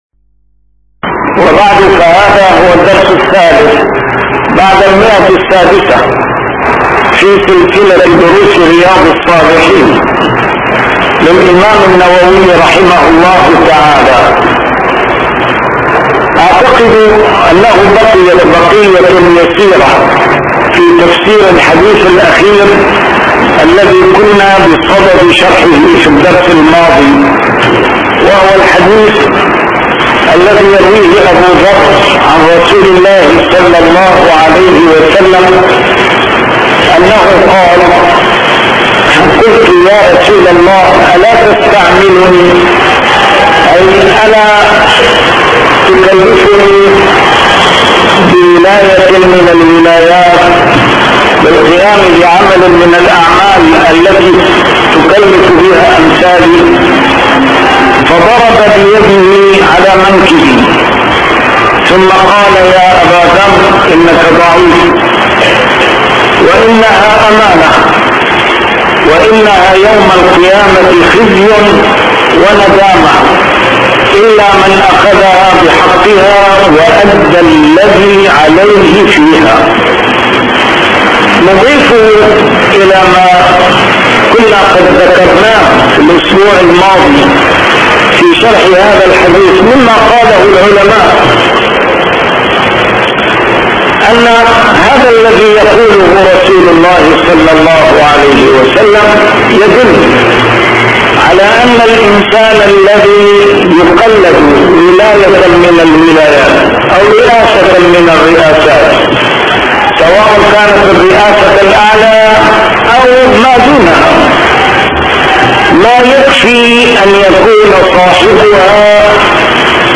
A MARTYR SCHOLAR: IMAM MUHAMMAD SAEED RAMADAN AL-BOUTI - الدروس العلمية - شرح كتاب رياض الصالحين - 603- شرح رياض الصالحين: النهي عن سؤال الإمارة